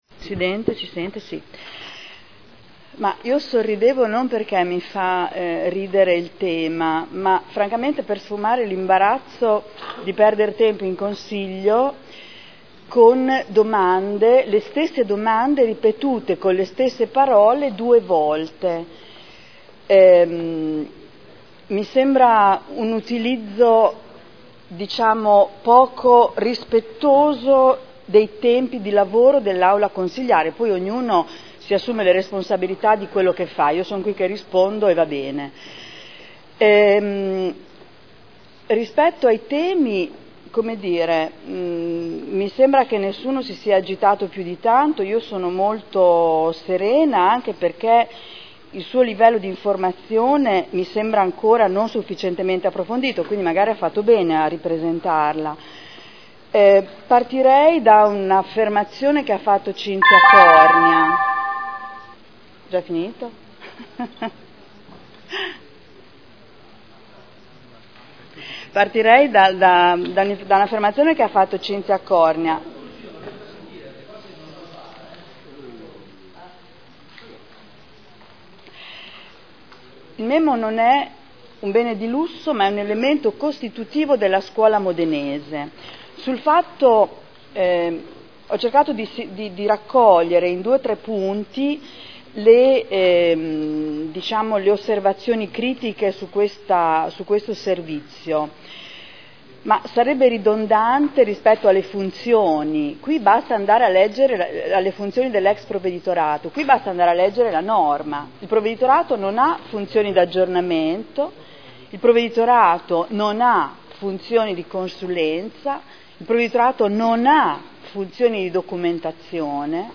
Seduta del 22/12/2011. Conclude dibattito sulle 2 Interrogazioni del consigliere Barberini (Lega Nord) avente per oggetto: “MEMO”